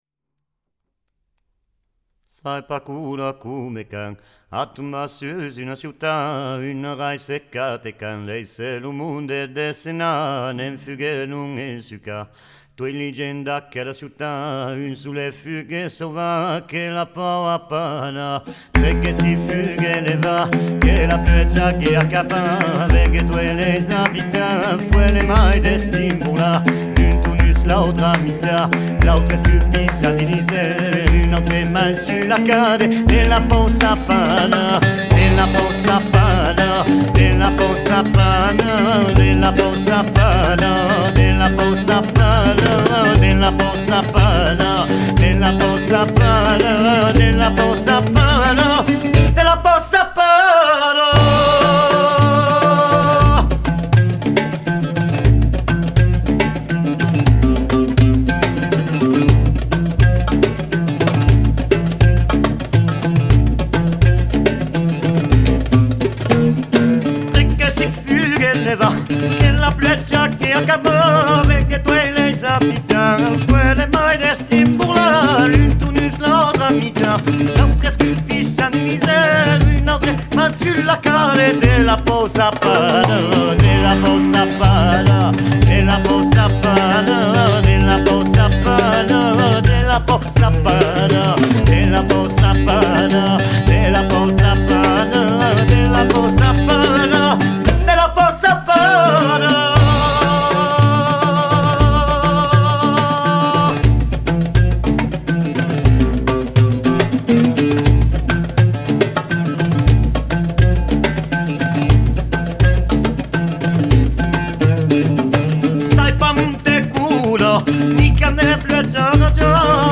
oud
percussions